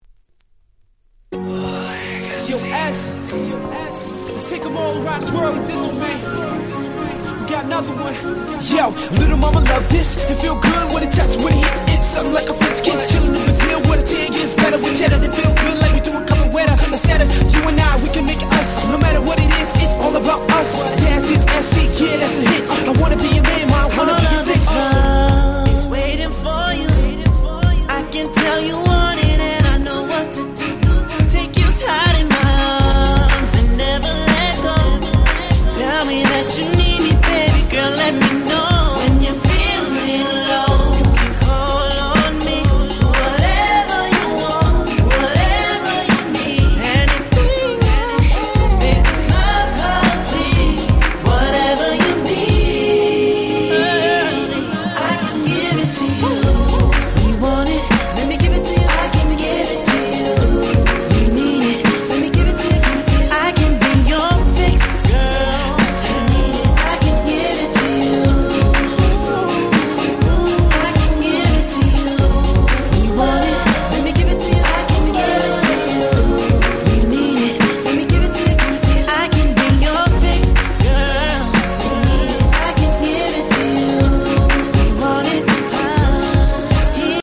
07' Nice UK R&B !!